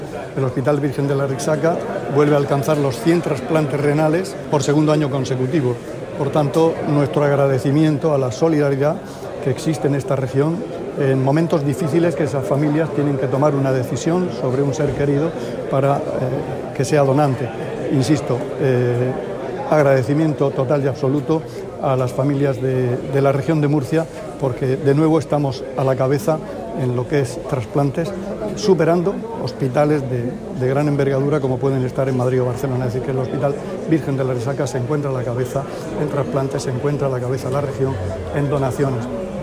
Declaraciones del consejero de Salud, Juan José Pedreño, sobre los trasplantes renales que, por segundo año consecutivo, han superado el centenar.